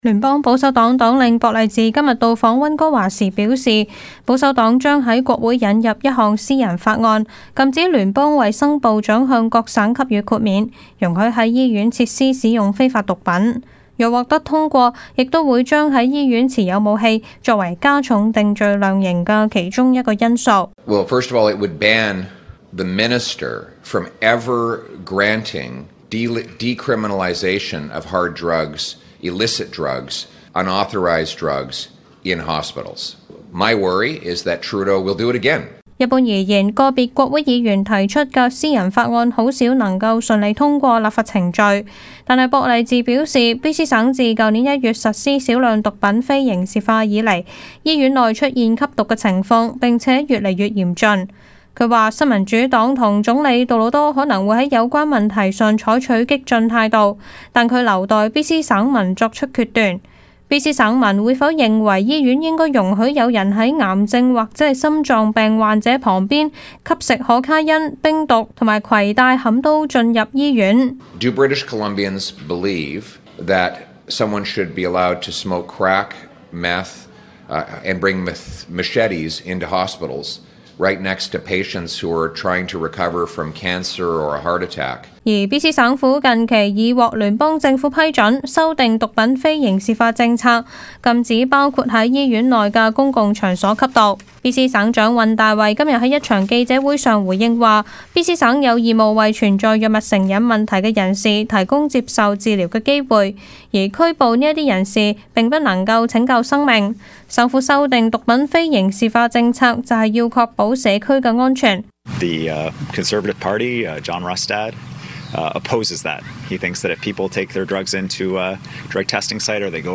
Canada/World News 全國/世界新聞
news_clip_18885.mp3